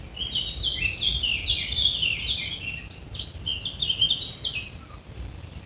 Sylvia atricapilla